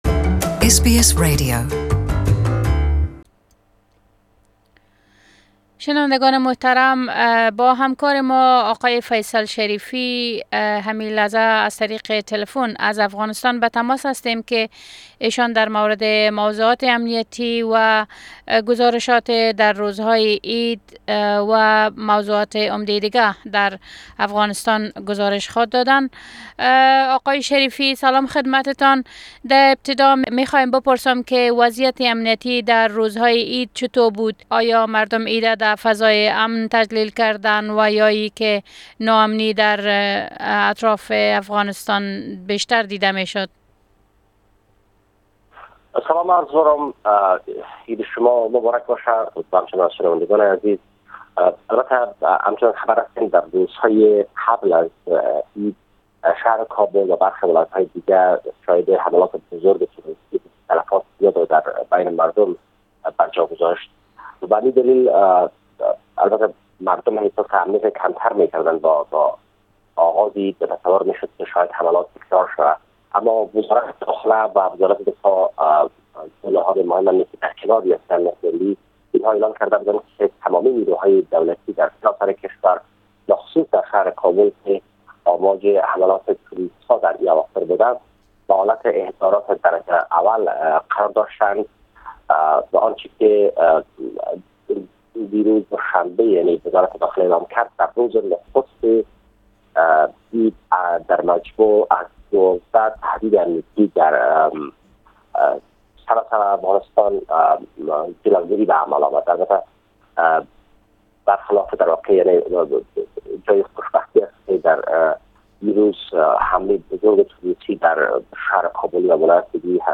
our corespondent's report from Afghanistan